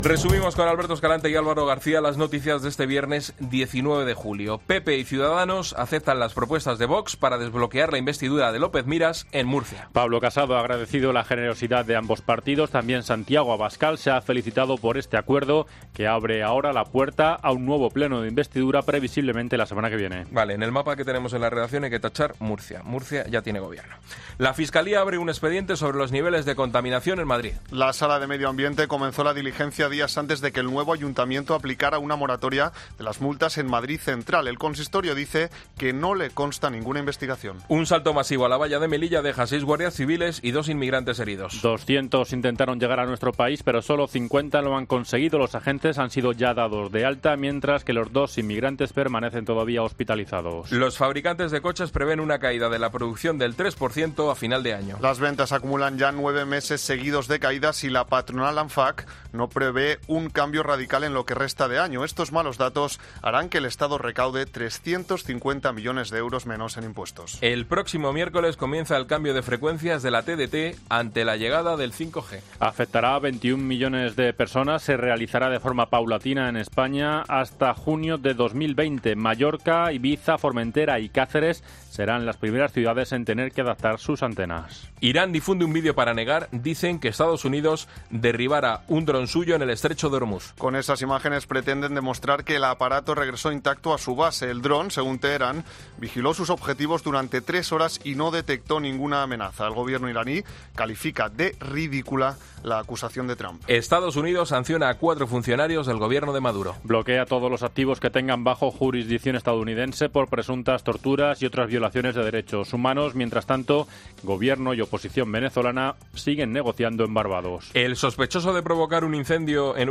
Boletín de noticias COPE del viernes 19 de julio a las 20:00 horas